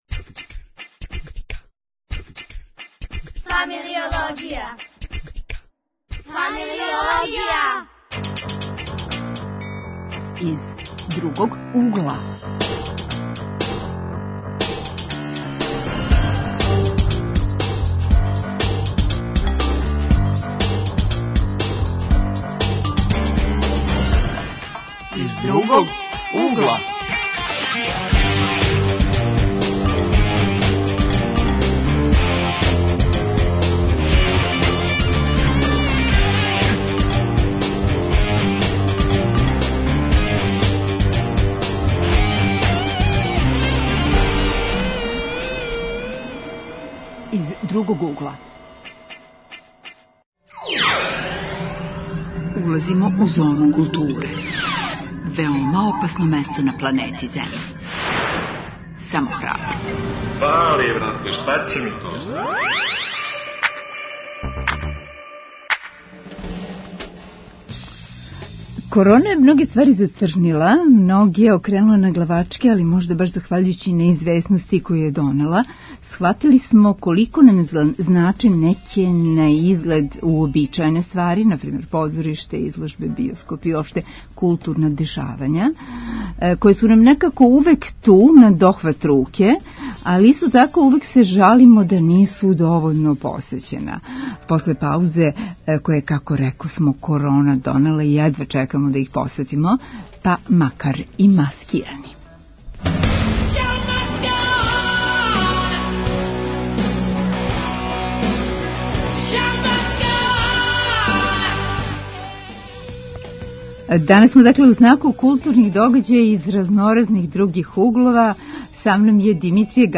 Гости су млади, заљубљеници у позориште.